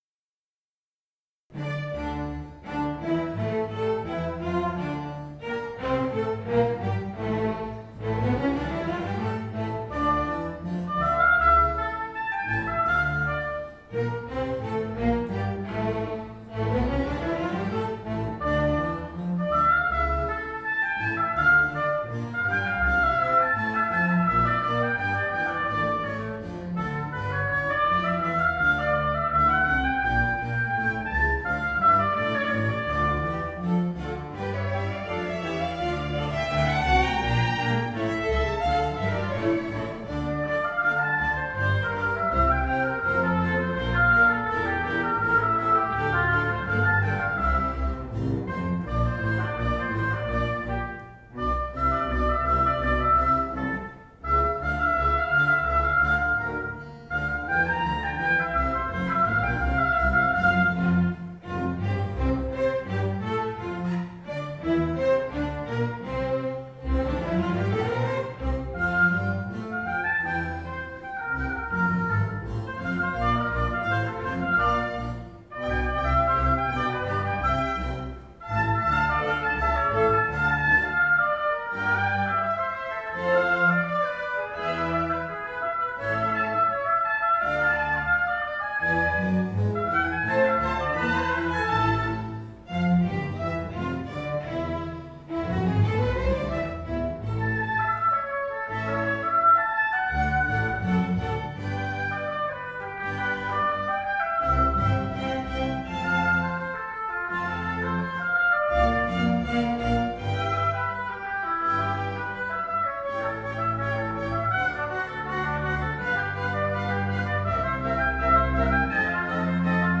Concerto per oboe in re minore di Alessandro Marcello, primo movimento
oboe
Dal concerto del 16 ottobre 2016